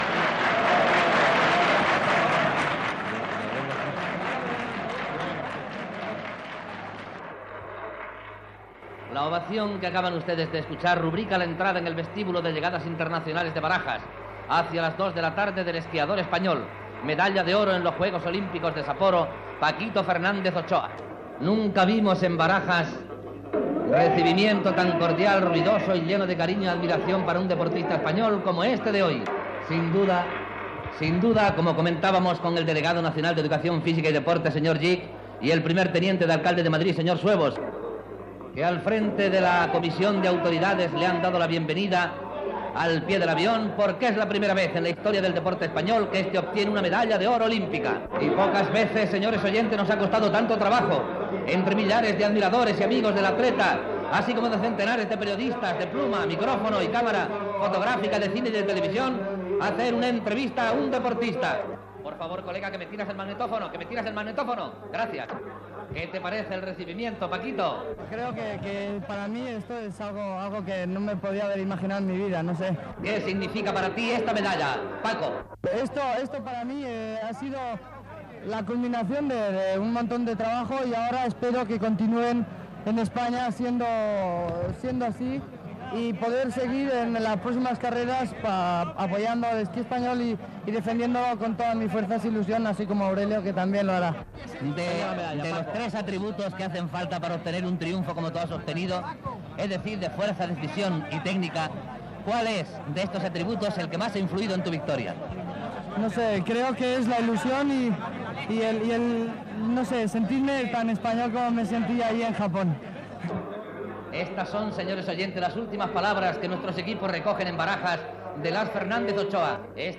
Arribada a l'aeroport de Madrid Barajas de l'esquiador Paquito Fernández Ochoa, que va aconseguir la primera medalla d'or en unos Jocs Olímpics d'hivern, a Sapporo (Japó).
Esportiu